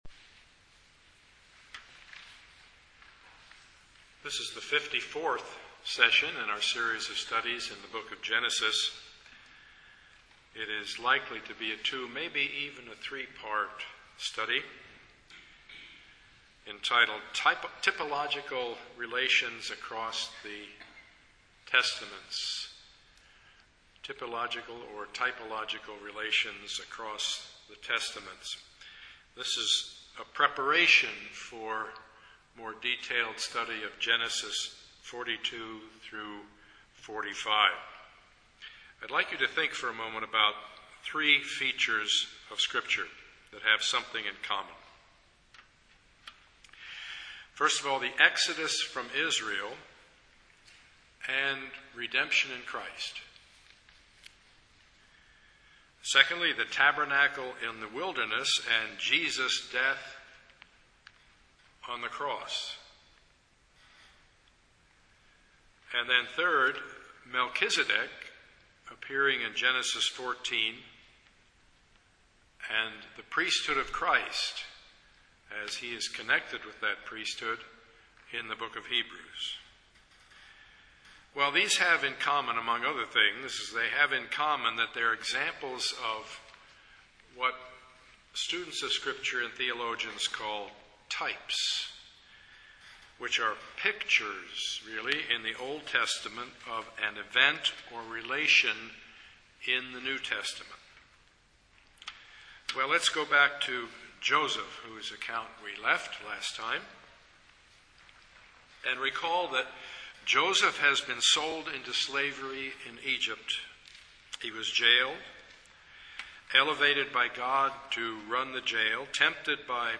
Passage: Genesis 42-45 Service Type: Sunday morning Part 54 of the Sermon Series